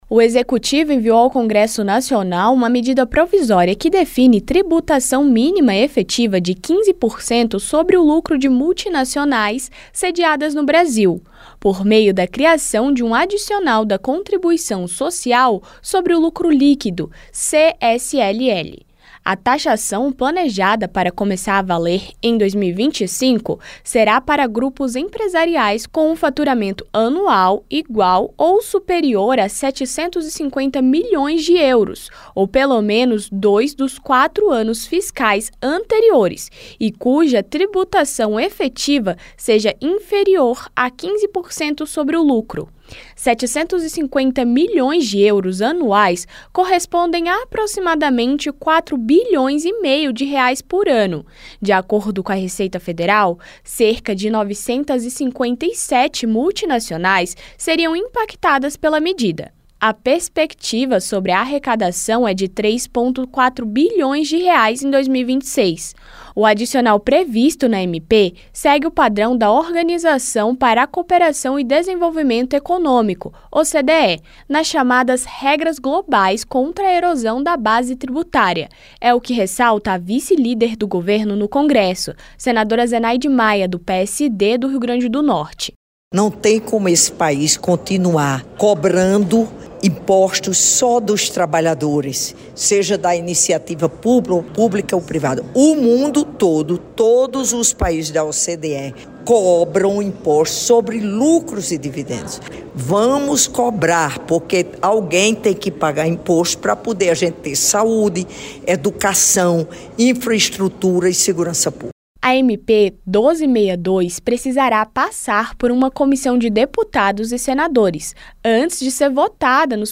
A vice-líder do governo no Congresso, senadora Zenaide Maia (PSD-RN), ressaltou que a taxação segue regras da Organização para a Cooperação e Desenvolvimento Econômico (OCDE). A MP passará por uma Comissão Mista antes de ser votada nos plenários da Câmara e do Senado.